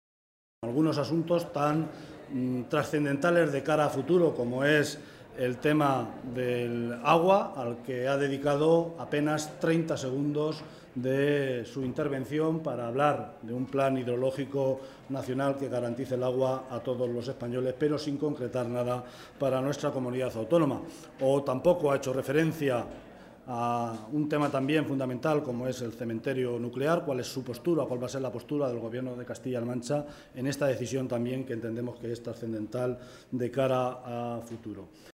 José Luis Martínez Guijarro, portavoz del Grupo Parlamentario Socialista en las Cortes de Castilla-La Mancha
Cortes de audio de la rueda de prensa